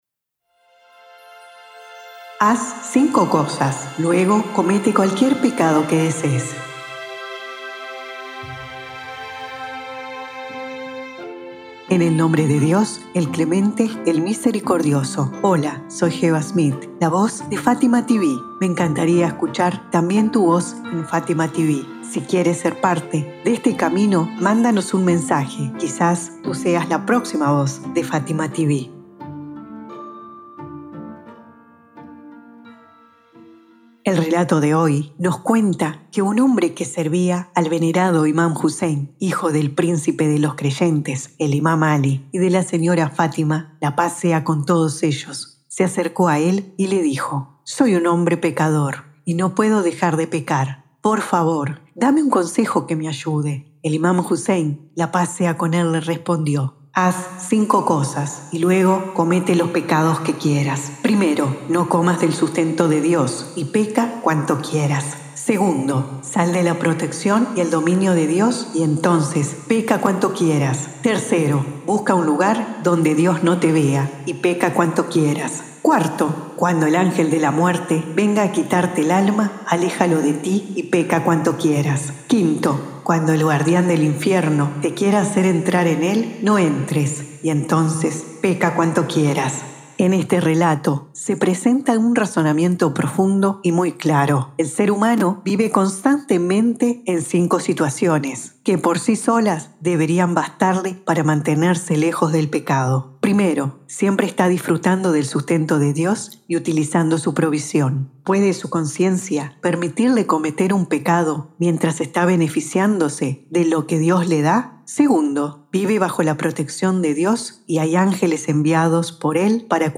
🎙 Locutora